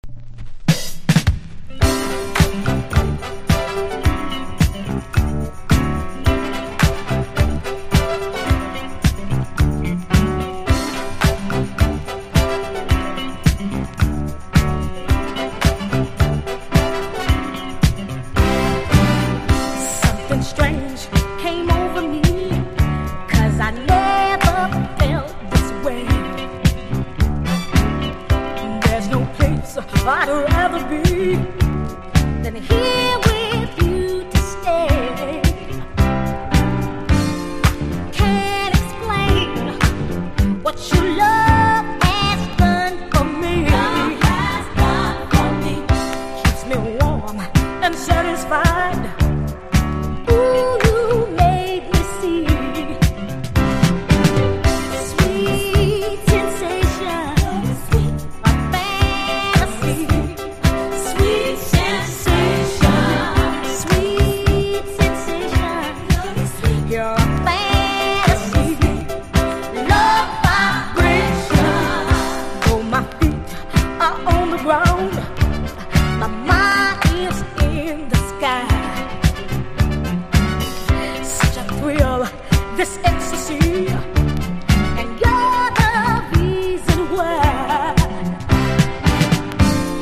所によりノイズありますが、リスニング用としては問題く、中古盤として標準的なコンディション。